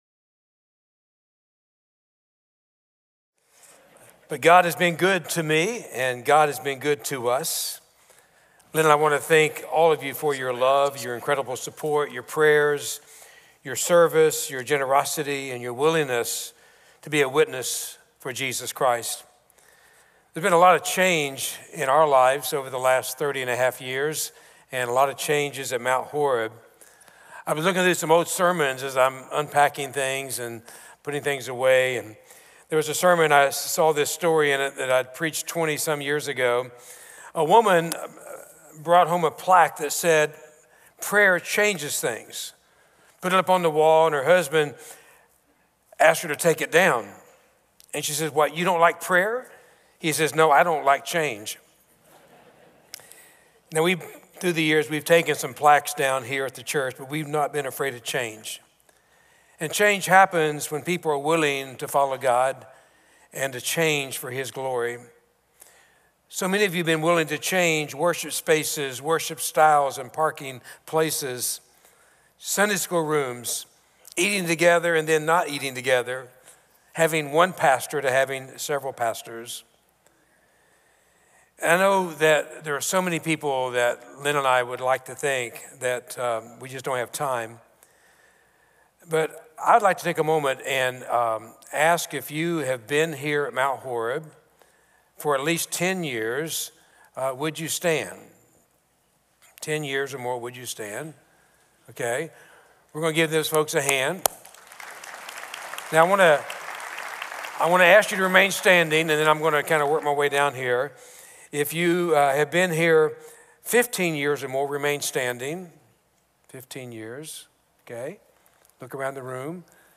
Contemporary Service